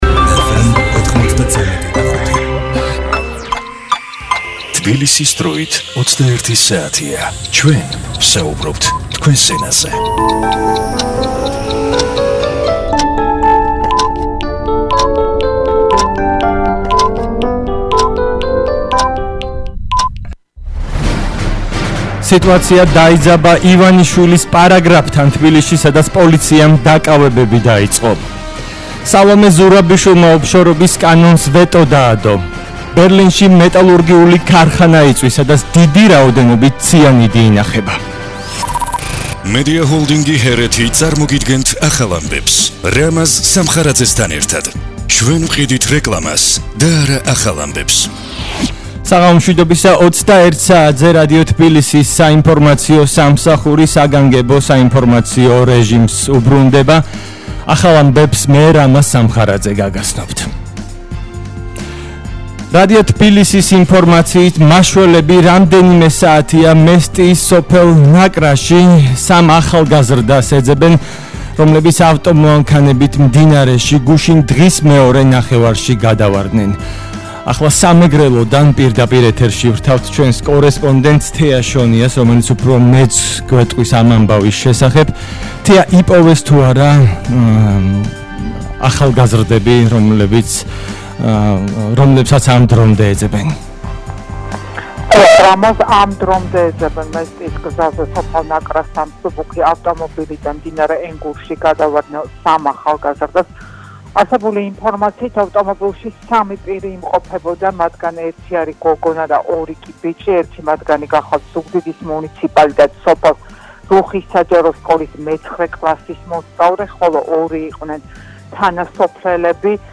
ახალი ამბები 21:00 სთ – 3 მაისის სპეციალური გამოშვება